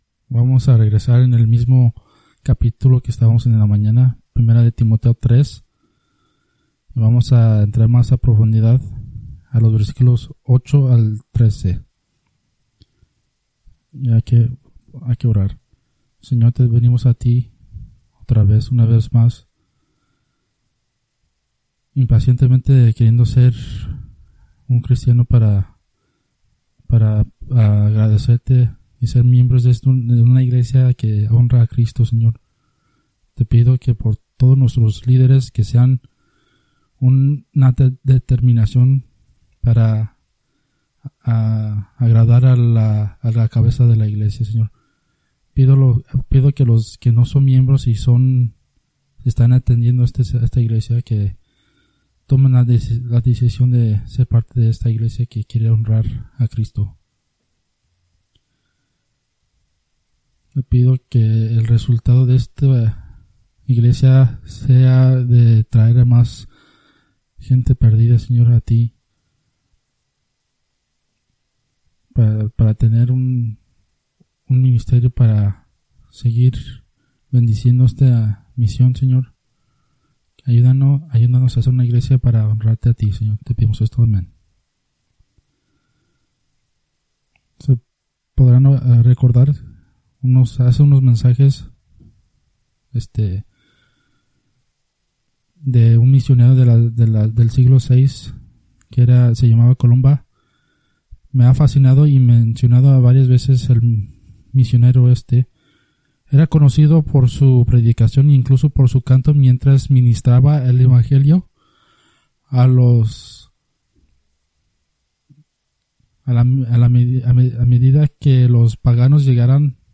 Preached May 5, 2024 from Escrituras seleccionadas